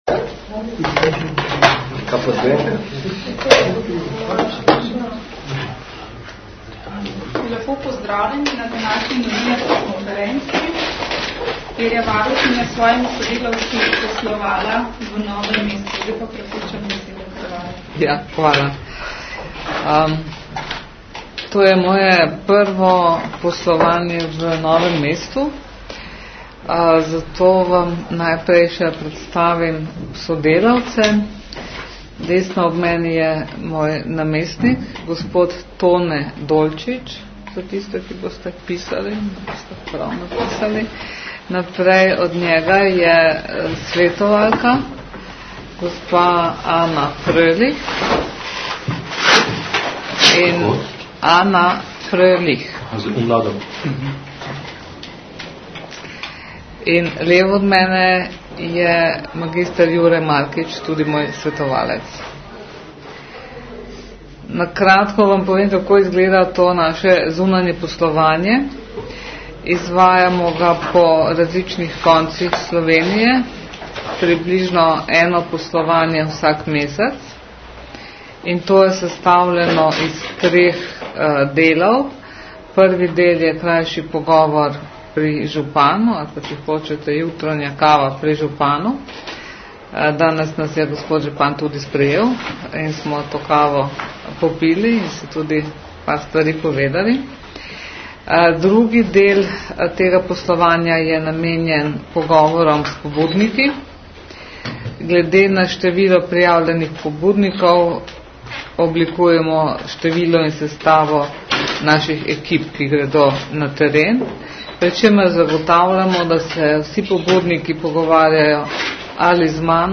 Zvočni posnetek novinarske konference (mp3, 7.76 MB)